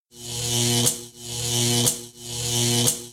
zap.mp3